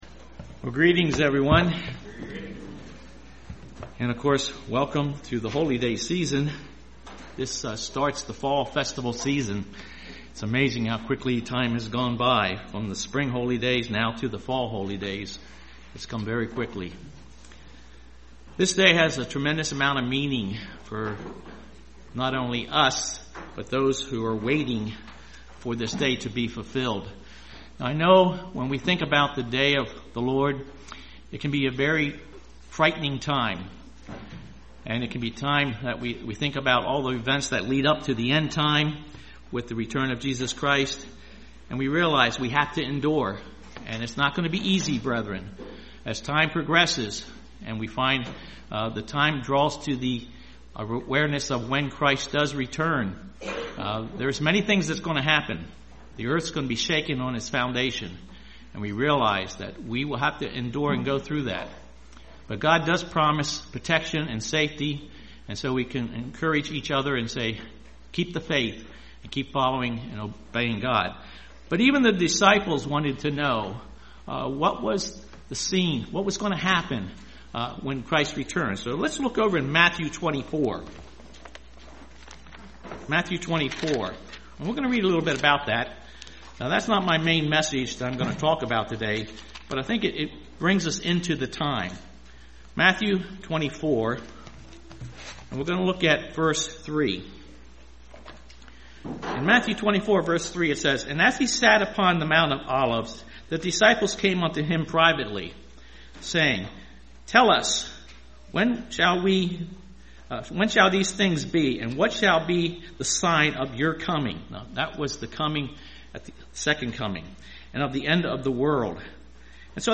Sermons
Given in Lehigh Valley, PA Lewistown, PA York, PA